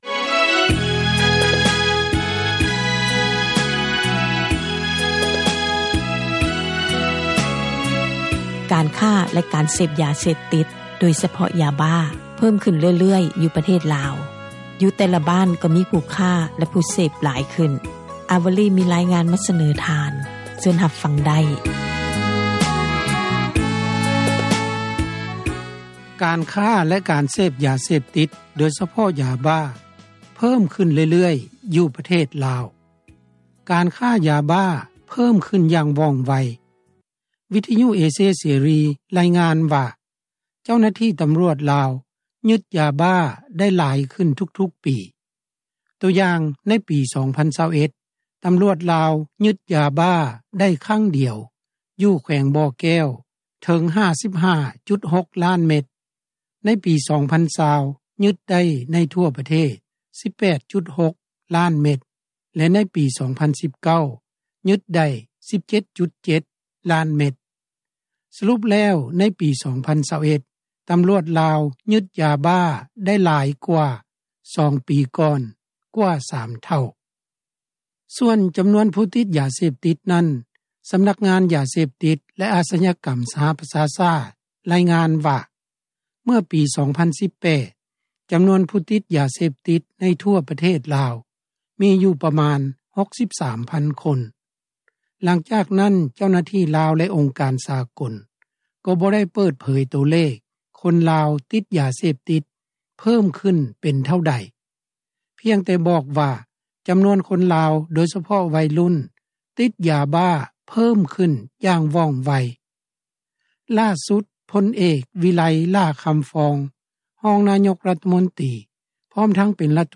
ຍ້ອນມີຄົນຕິດ ຢາເສບຕິດຫຼາຍໃນຊຸມຊົມ, ພົນເອກ ວິໄລ ຫຼ້າຄຳຟອງ ຍັງສເນີໃຫ້ຕັ້ງສູນບຳບັດ ຢູ່ແຕ່ລະບ້ານໃຫຍ່ໆ. ຕໍ່ບັນຫາການຣະບາດຂອງຢາເສບຕິດ ໃນຊຸມຊົນນີ້ ຂ້າພະເຈົ້າ ໄດ້ສັມພາດຜູ້ນຳຊຸມຊົນ ຂອງບ້ານໃຫຍ່ ບ້ານນຶ່ງ ໃນແຂວງບໍຣິຄຳໄຊ.
ຜູ້ນຳຊຸມຊົນທ່ານນີ້ ຊຶ່ງບໍ່ປະສົບບອກຊື່ ຕອບວ່າ: